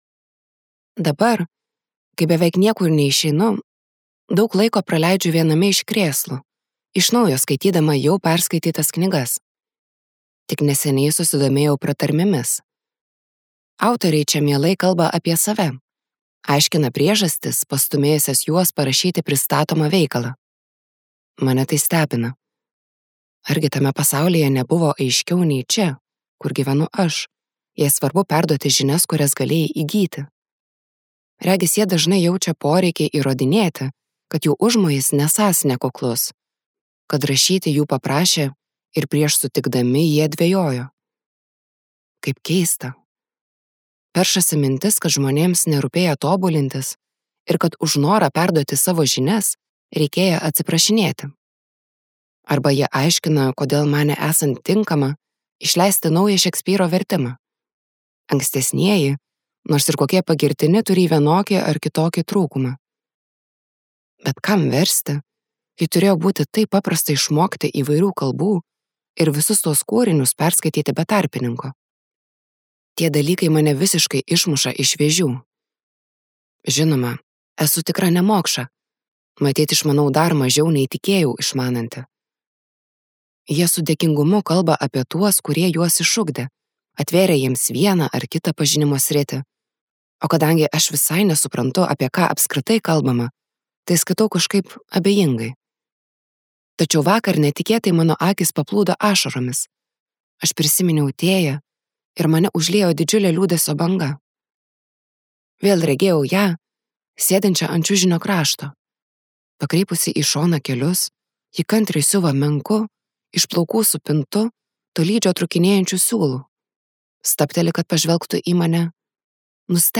Distopinė Jacqueline Harpman audioknyga „Aš, nepažinusi vyrų“ yra apie moterų gyvenimą nelaisvėje ir pabėgimą į nepažįstamą ir keistą pasaulį.